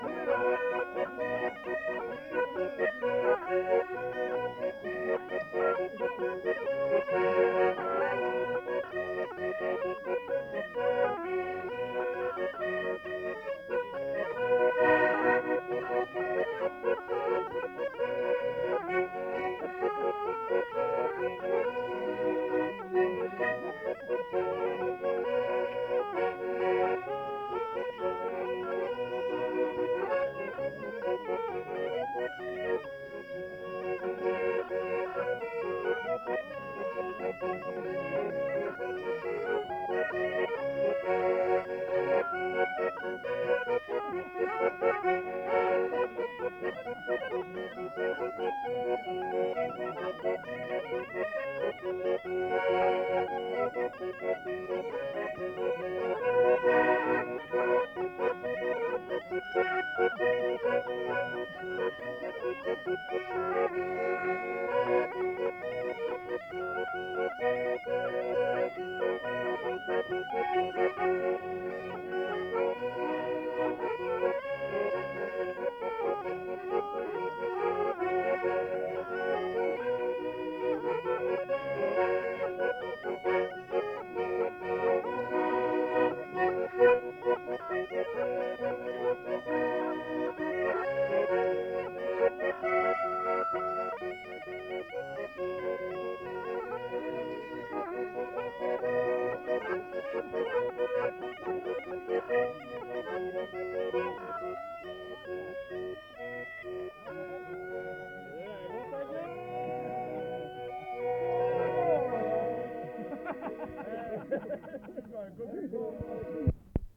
Aire culturelle : Viadène
Genre : morceau instrumental
Instrument de musique : cabrette ; accordéon chromatique
Danse : marche (danse)
Notes consultables : Plusieurs airs.